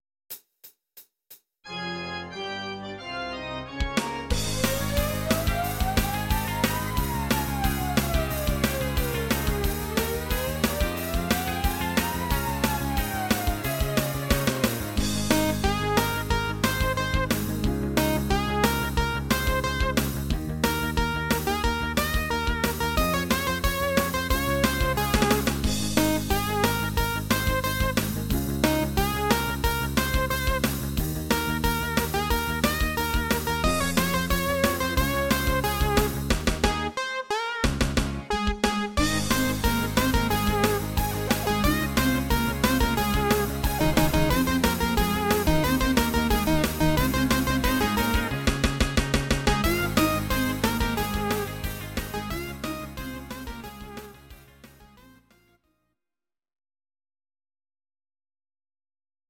Ab
Audio Recordings based on Midi-files
Pop, 2000s